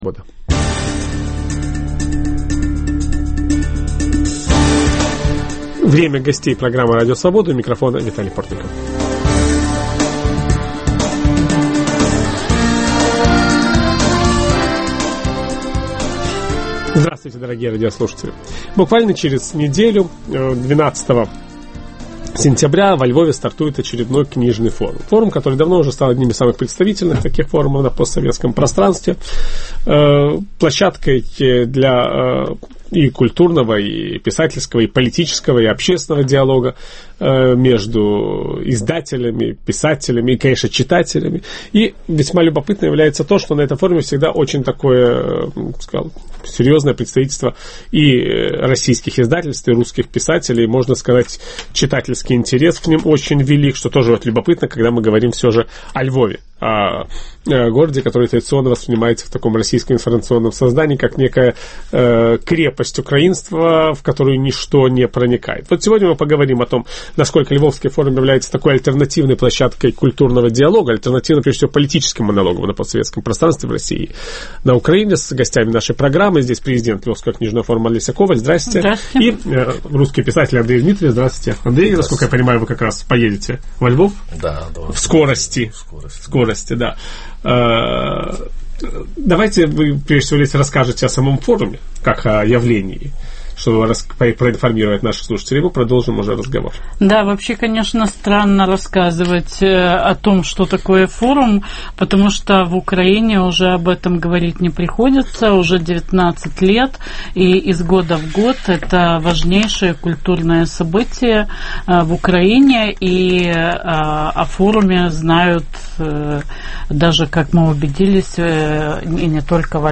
Может ли культурный диалог стать альтернативой политическому монологу? О возможностях взаимопонимания русской и украинской культур беседуем